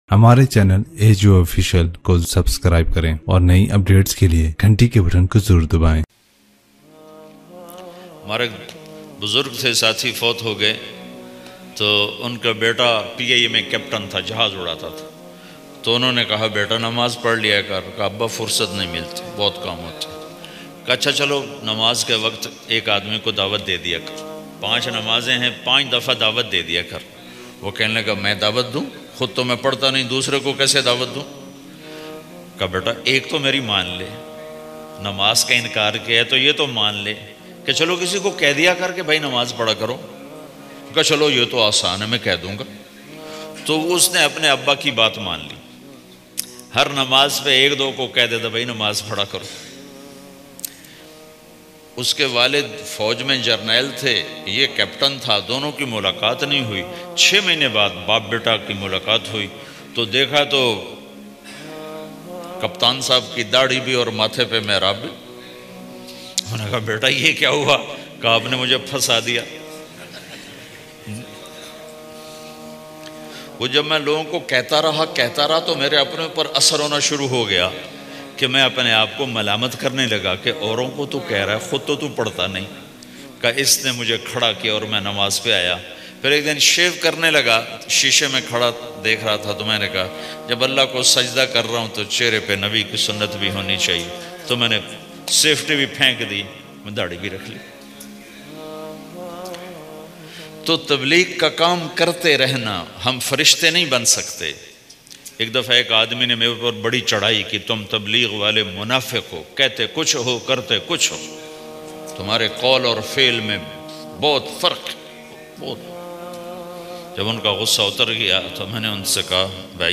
Namaz Na Parhna Maulana Tariq Jameel Latest Bayan mp3